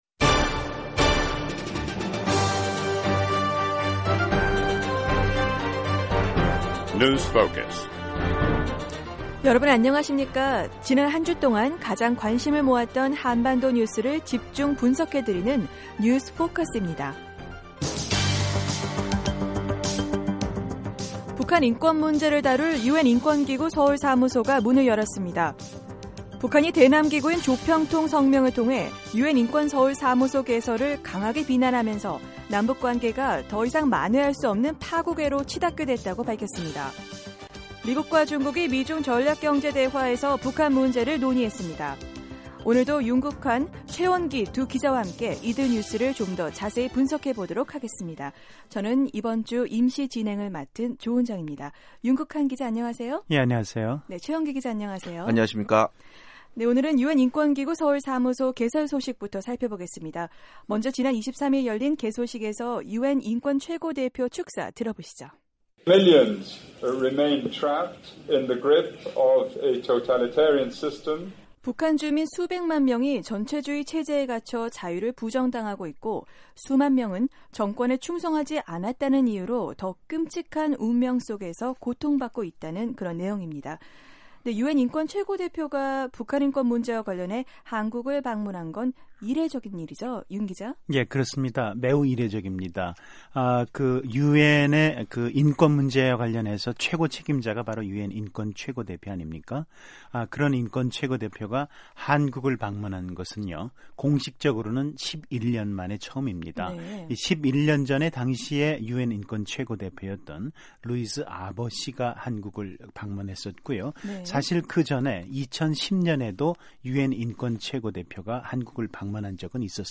지난 한 주 동안 가장 관심을 모았던 한반도 뉴스를 집중 분석해 드리는 뉴스 포커스입니다. 오늘은 북한 인권 문제를 전문적으로 다룰 유엔 인권기구 서울사무소가 23일 문을 열었다는 소식과 함께 북한 당국이 유엔 인권 사무소 개설에 강력 반발했다는 내용 그리고 미국과 중국이 미-중 전략경제대화를 갖고 북한 문제를 논의한 소식 살펴봤습니다.